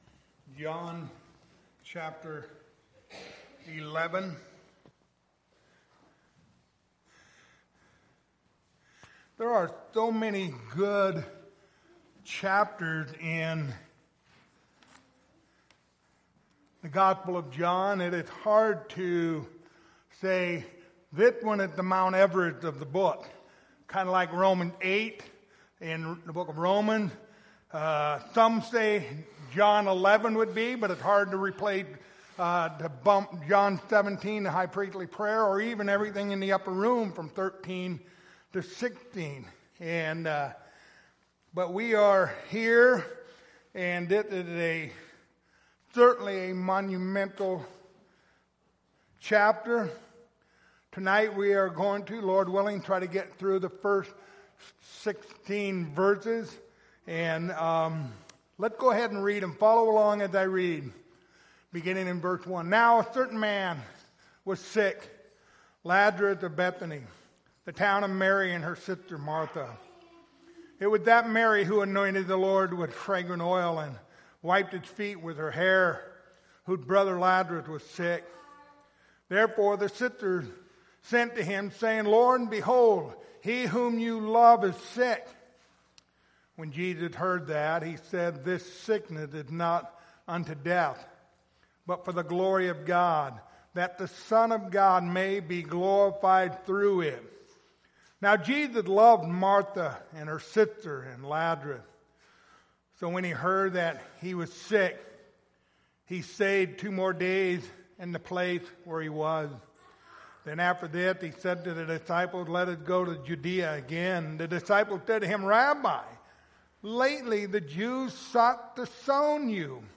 Passage: John 11:1-16 Service Type: Wednesday Evening Topics